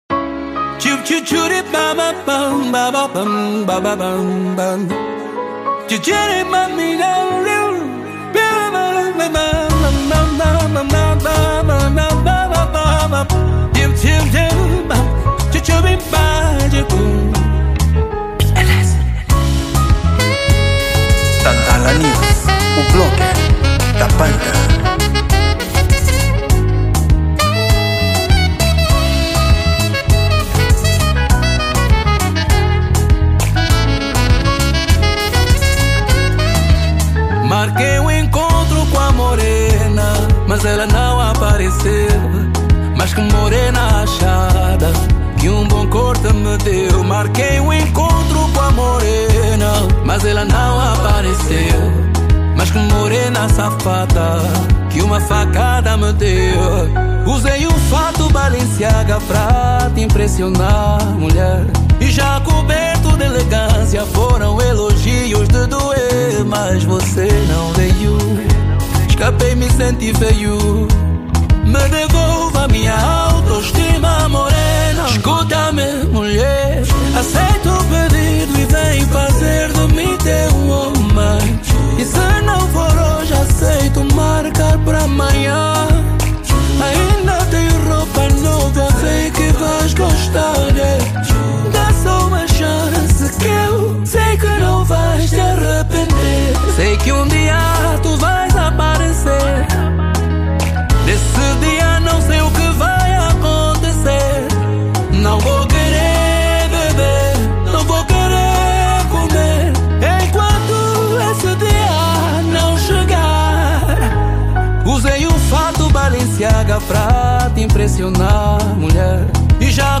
Género: Kizomba